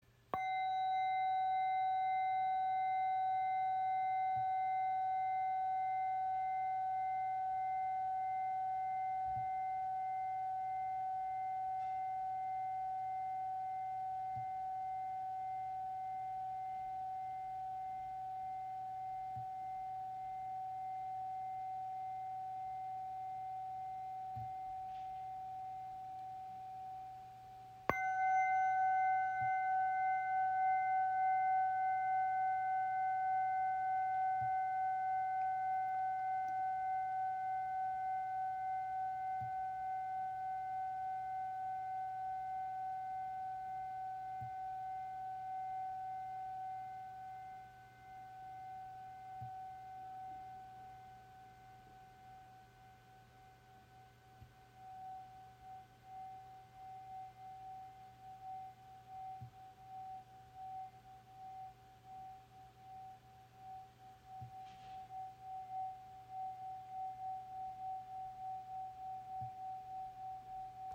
• Icon Sehr lange Resonanz mit besonders reinen Schwingungen
Schwebende Klangglocken | Ausführung Cosmos | In der Grösse 8 cm
Handgeschmiedete Klangglocken in der Ausführung Cosmo mit leuchtenden kosmischen Obertönen.
Ihr Klang ist klar, lichtvoll und rein.